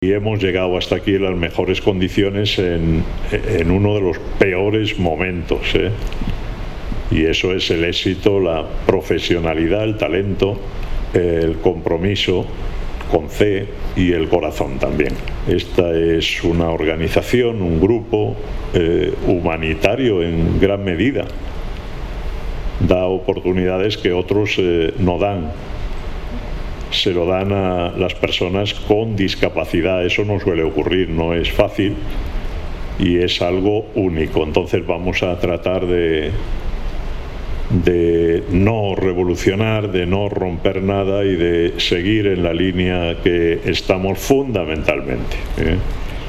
dijo formato MP3 audio(1,09 MB)Lo hizo durante la clausura del último Comité de Coordinación General (CCG) ordinario, celebrado presencial y telemáticamente el  9 y 10 de junio en Madrid, que reunió a más de 200 personas, responsables de todos los equipos de gestión de toda España.